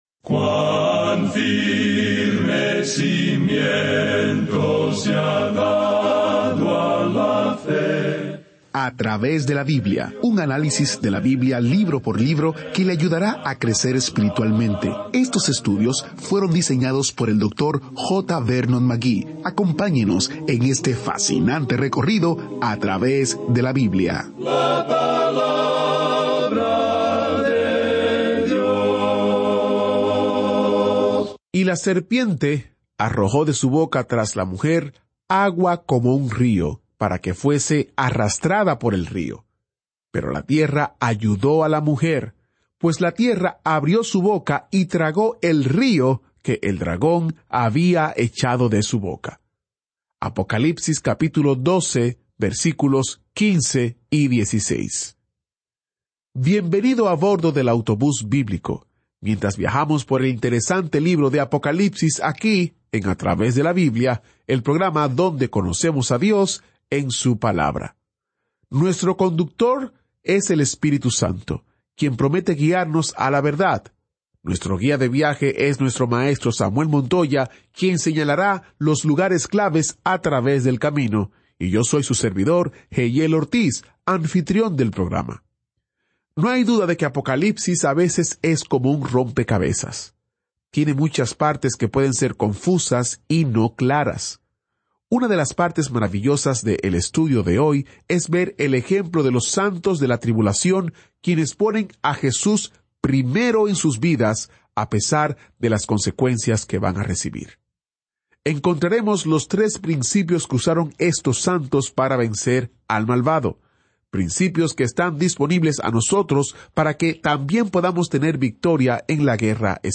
Este es un programa de radio diario de 30 minutos que sistemáticamente lleva al oyente a través de toda la Biblia.